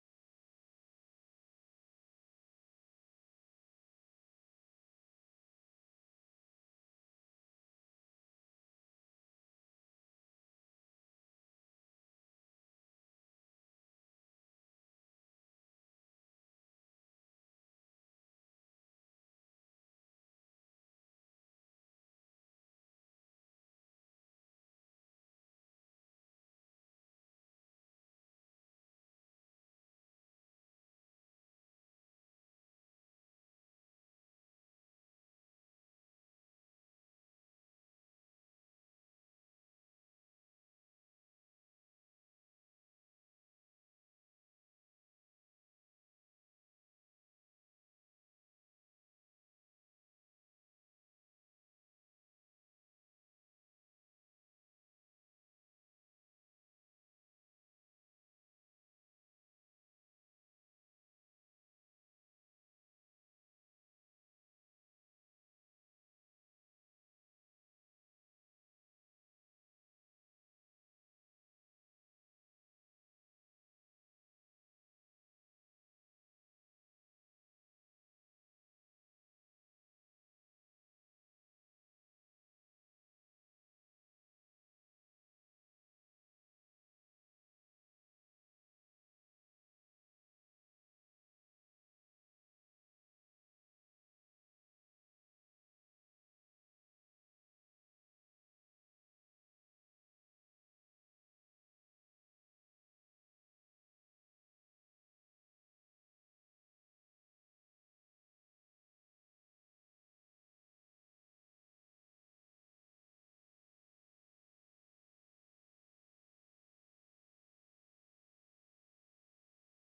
audiodescriptie_pzh_animatie-2_water-te-veel_1.mp3